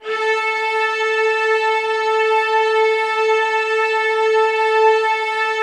Index of /90_sSampleCDs/Optical Media International - Sonic Images Library/SI1_Lush Strings/SI1_Soft Lush